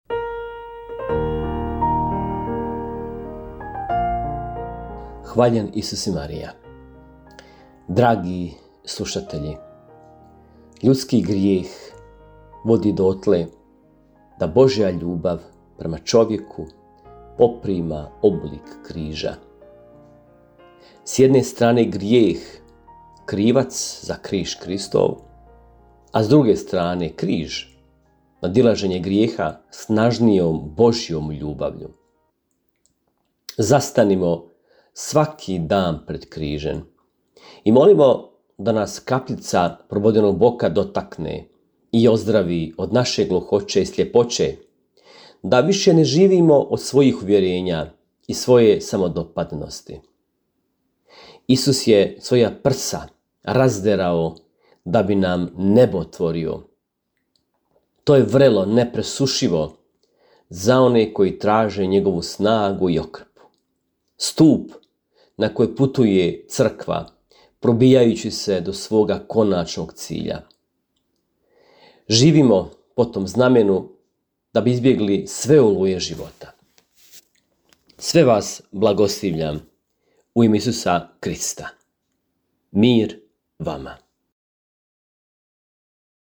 Kratku emisiju ‘Duhovni poticaj – Živo vrelo’ slušatelji Radiopostaje Mir Međugorje mogu čuti od ponedjeljka do subote u 3 sata, te u 7:10. Emisije priređuju svećenici i časne sestre u tjednim ciklusima.